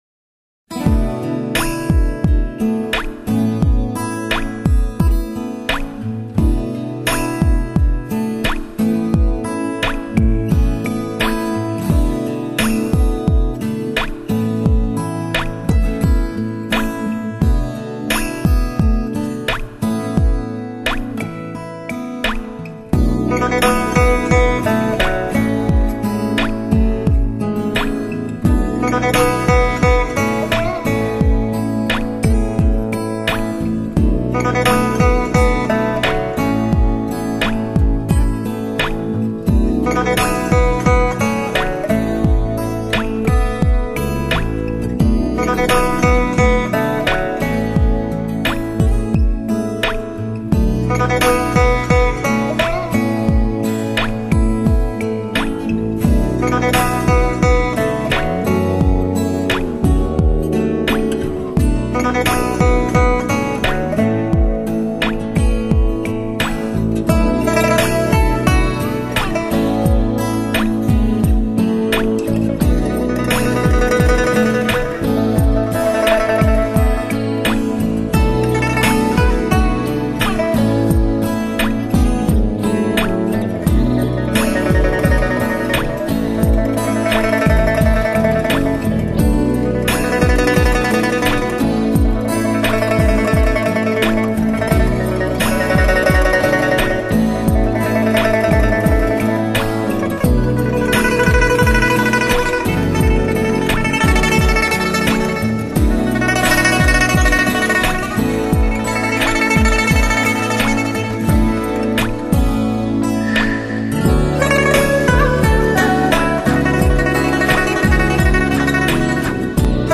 还有琵琶合着竹乐器那种特有的水音。
整首乐曲充满一种弹跳性， 吉他与琵琶的音色在其中竟能配合得天衣无缝。